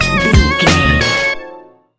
Ionian/Major
C♭
laid back
Lounge
sparse
new age
chilled electronica
ambient
atmospheric